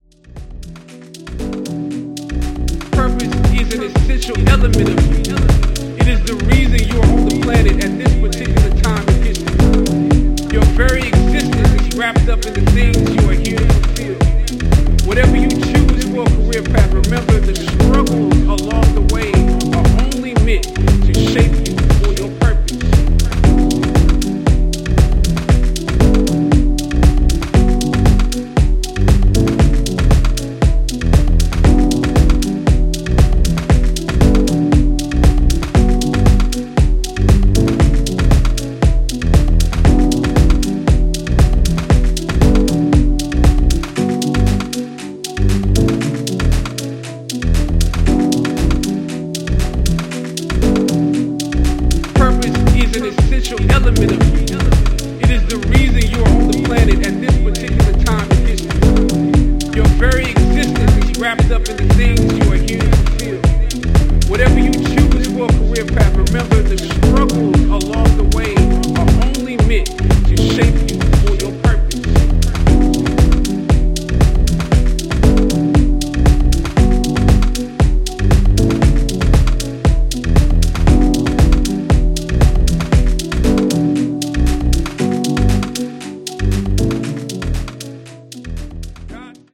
US産らしい厚みあるミニマル/ハウスを融合したナイスなデトロイト・ディープ・ハウスを展開する全4トラック。
ジャンル(スタイル) HOUSE / DEEP HOUSE